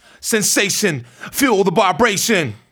RAPHRASE03.wav